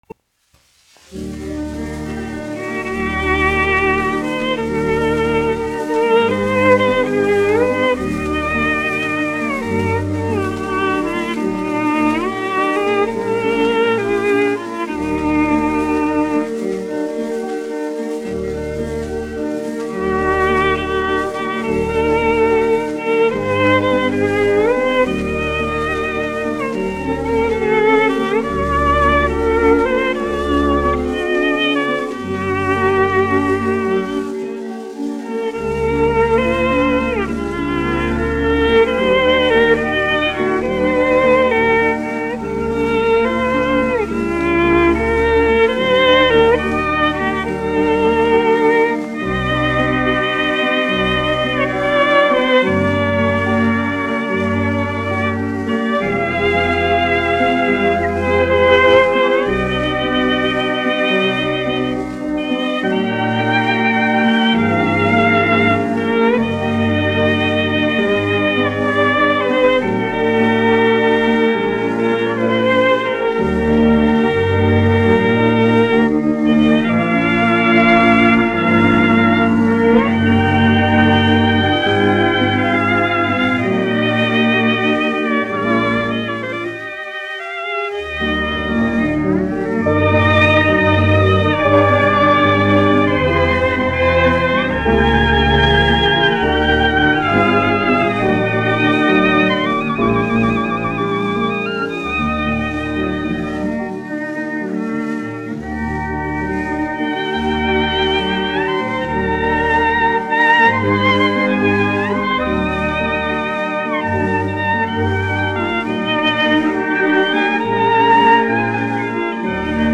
1 skpl. : analogs, 78 apgr/min, mono ; 25 cm
Ziemassvētku mūzika
Orķestra mūzika, aranžējumi
Skaņuplate